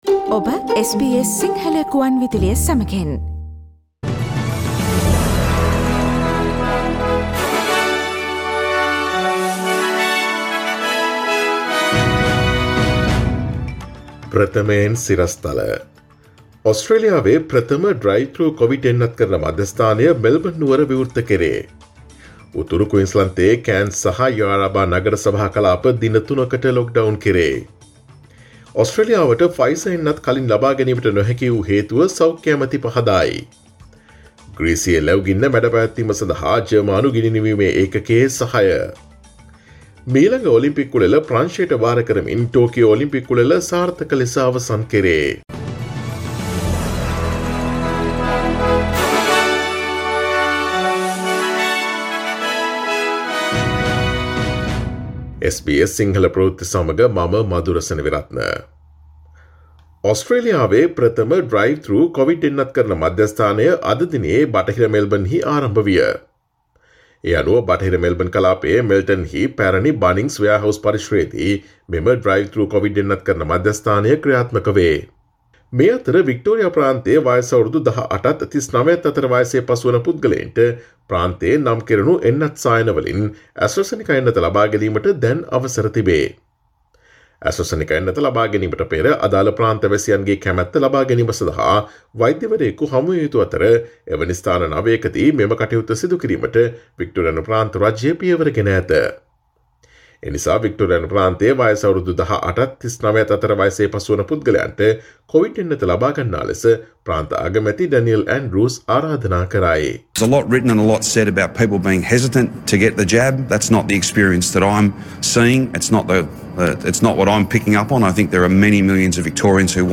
ඔස්ට්‍රේලියාවේ නවතම පුවත් මෙන්ම විදෙස් පුවත් සහ ක්‍රීඩා පුවත් රැගත් SBS සිංහල සේවයේ 2021 අගෝස්තු මස 09 වන දා සඳුදා වැඩසටහනේ ප්‍රවෘත්ති ප්‍රකාශයට සවන්දෙන්න.